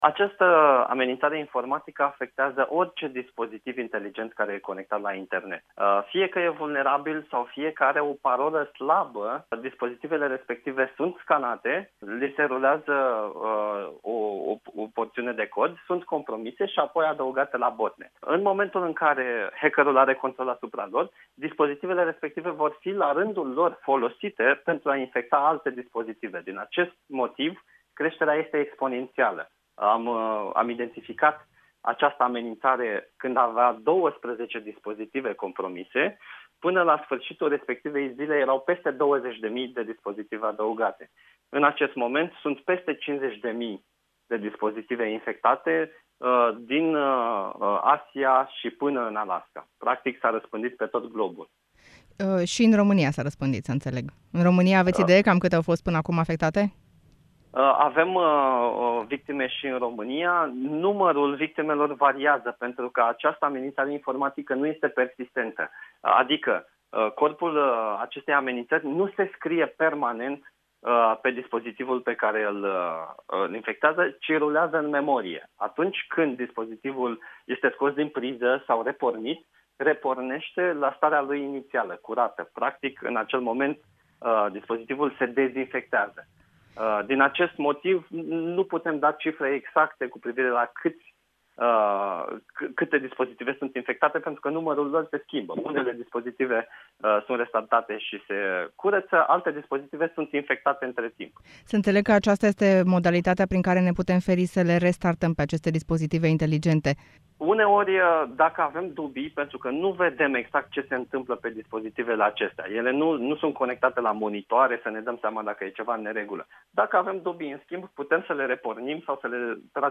Interviul integral